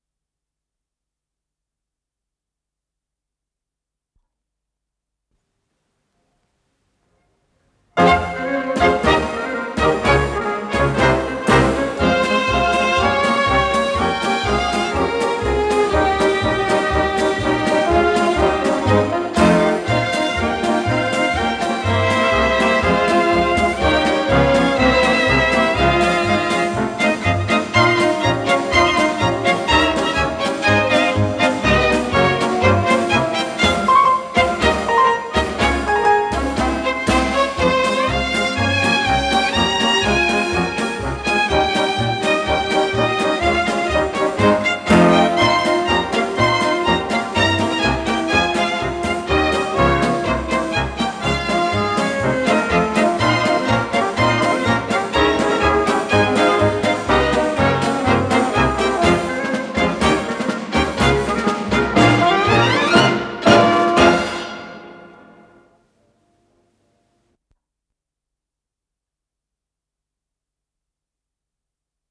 ПодзаголовокИнструментальная заставка, фа минор